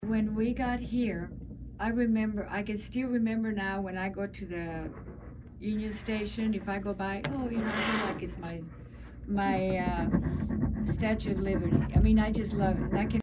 Oral Interview